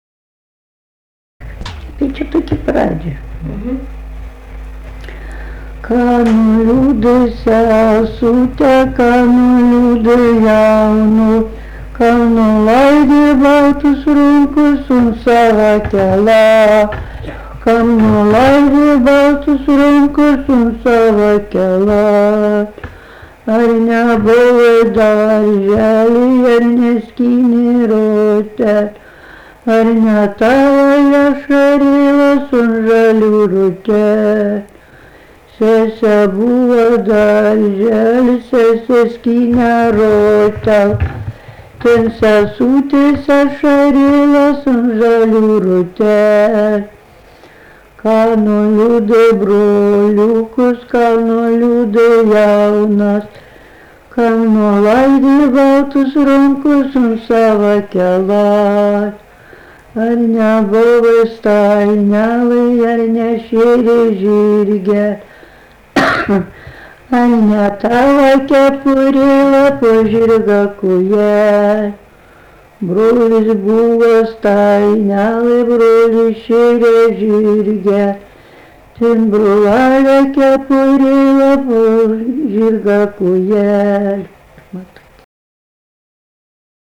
daina, vestuvių
Jurgėnai
vokalinis